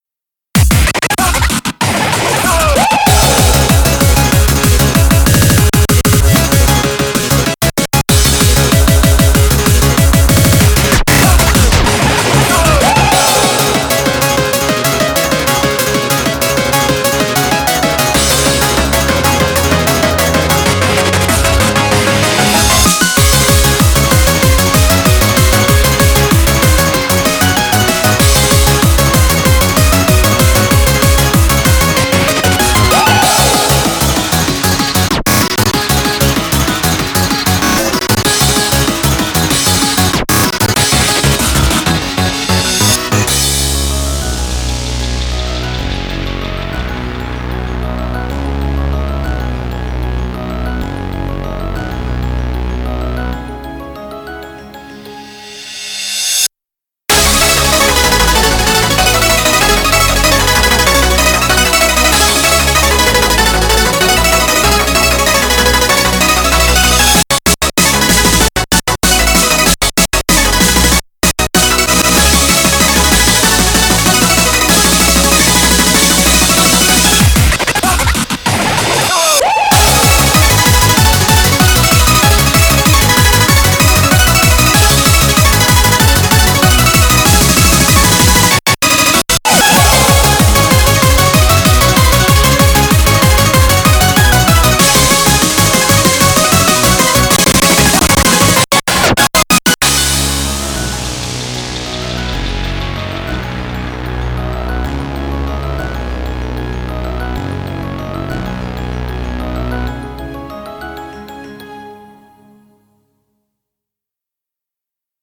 BPM191-382
Audio QualityPerfect (High Quality)